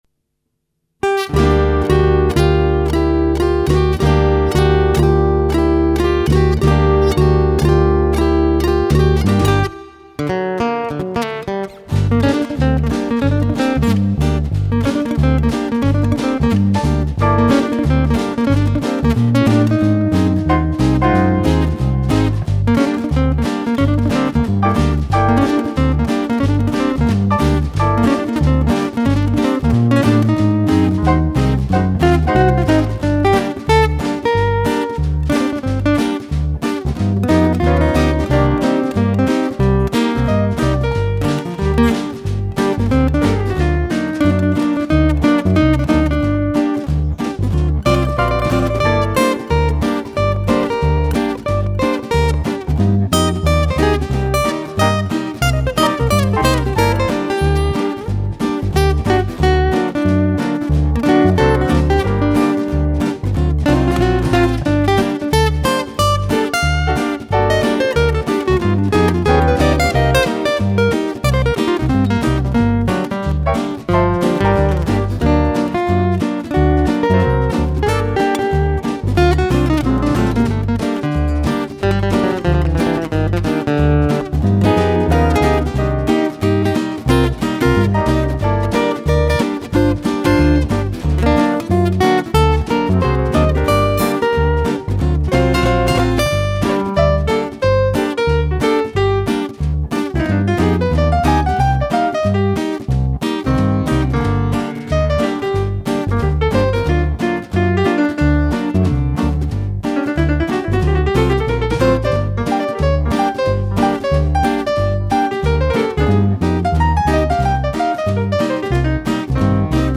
Chitarre e Basso
Pianoforte e Vibrafono
Batteria